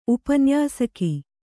♪ upanyāsaki